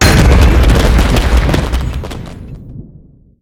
use_totem.ogg